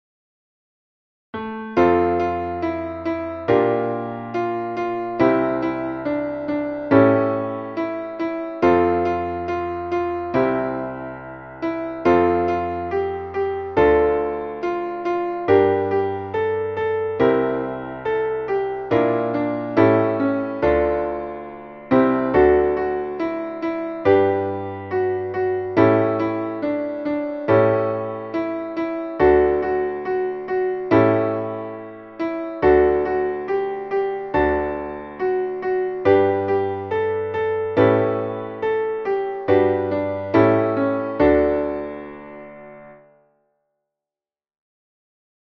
Traditionelles Winterlied